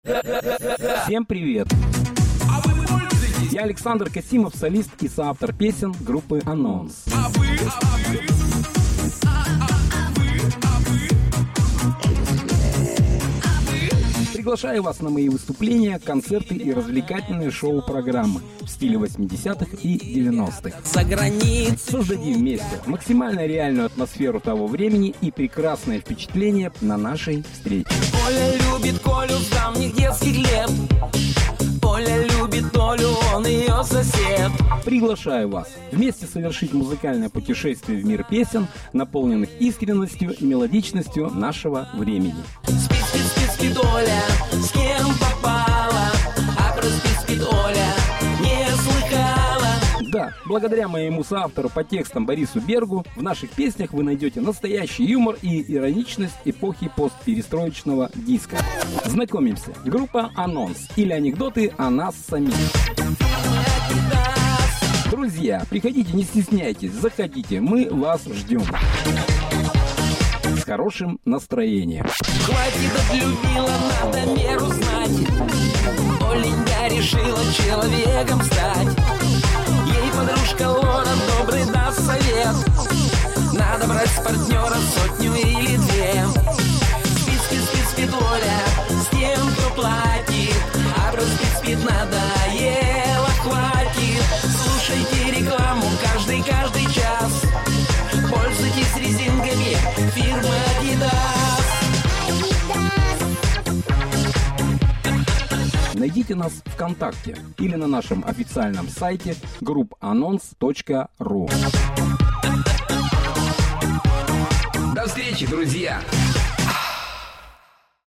01 Приветствие от группы Анонс!
01-privetstvie-ot-gruppyi-anons!-rabotaem---kontsertyi,-korporativyi,-yubilei.mp3